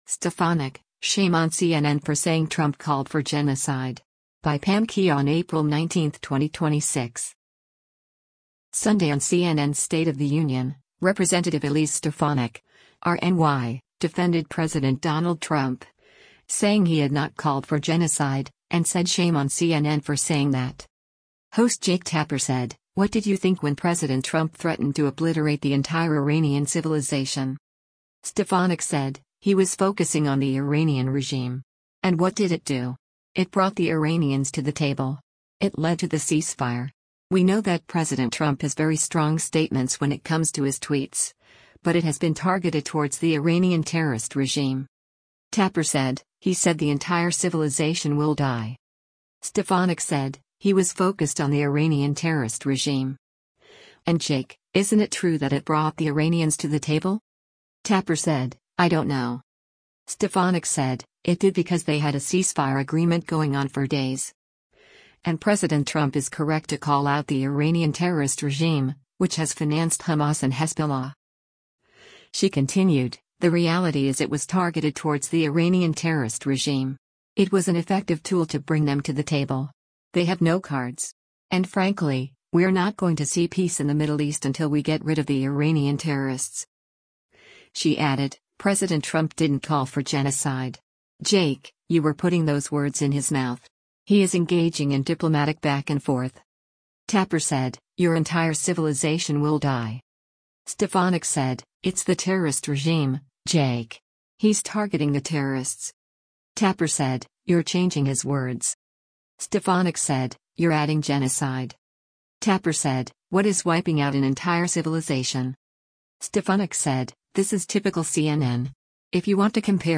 Sunday on CNN’s “State of the Union,” Rep. Elise Stefanik (R-NY) defended President Donald Trump, saying he had not called for genocide, and said “shame on CNN for saying that.”